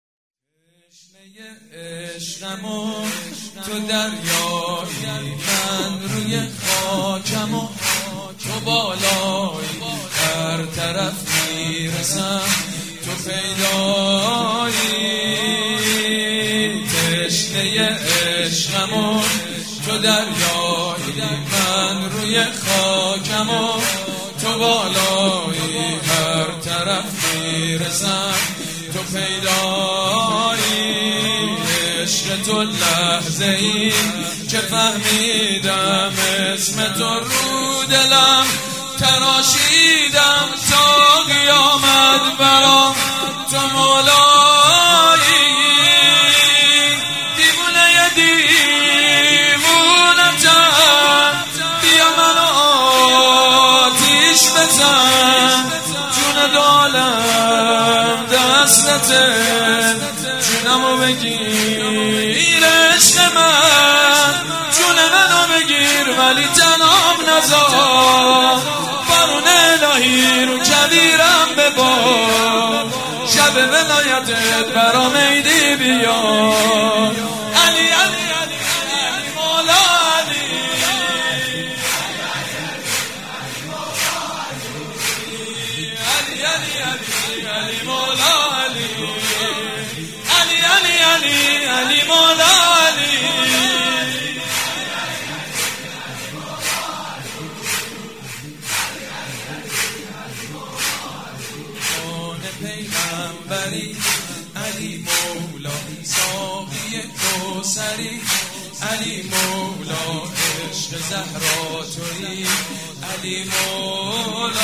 تشنه عشقم و تو دریایی از بنی فاطمه/ مولودی غدیر (فیلم، صوت، متن) مولودی خوانی تشنه عشقم و تو دریایی ویژه عید غدیر با صدای سید مجید بنی فاطمه که در هیات ریحانه الحسین تهران در سال 1395 اجرا شده است.